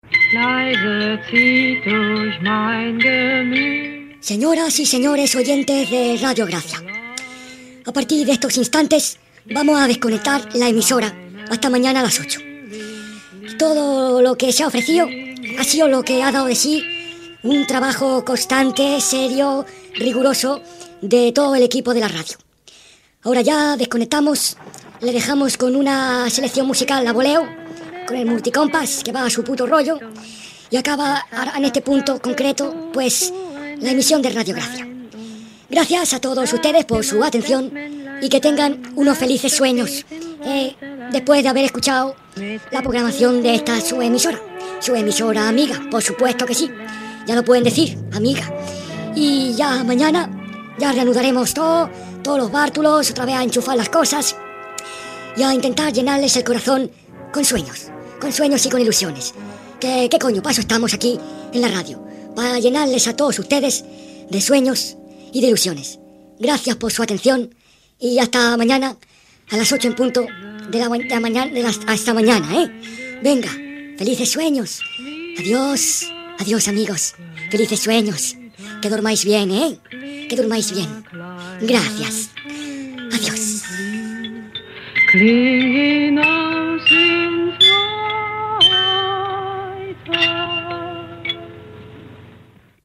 Tancament de l'emissió